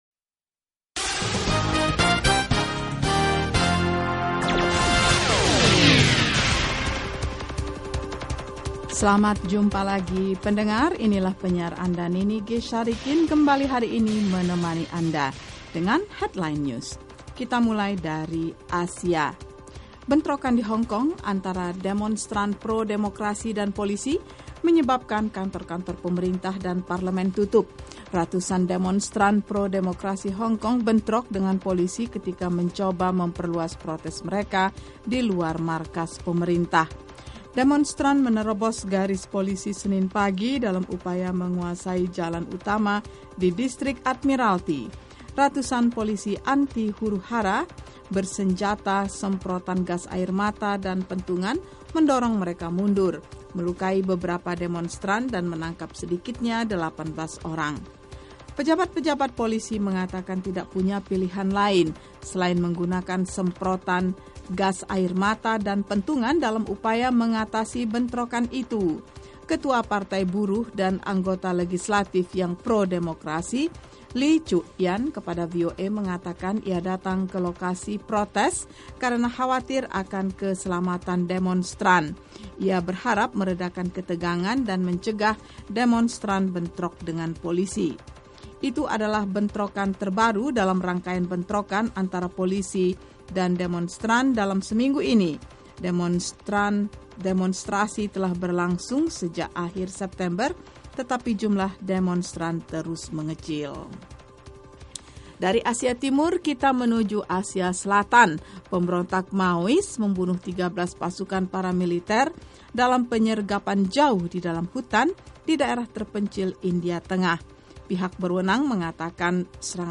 Simak berita terkini setiap 30 menit langsung dari Washington dalam Headline News, bersama para penyiar VOA yang setia menghadirkan perkembangan terakhir berita-berita internasional.